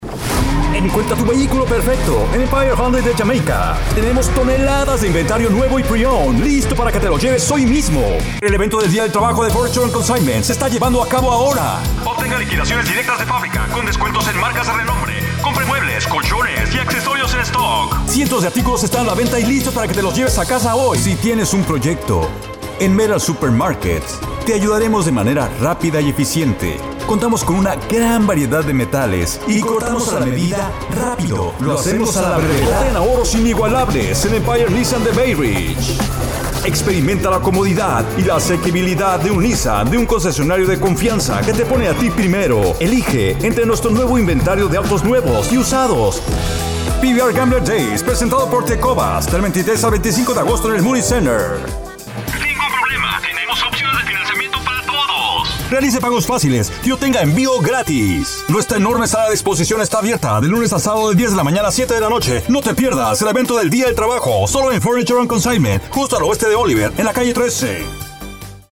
Australian, warm, friendly, fun voice over with great flexibility.
0805Male.mp3